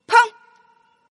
Index of /client/common_mahjong_tianjin/mahjongjinghai/update/1134/res/sfx/tianjin/woman/
peng.mp3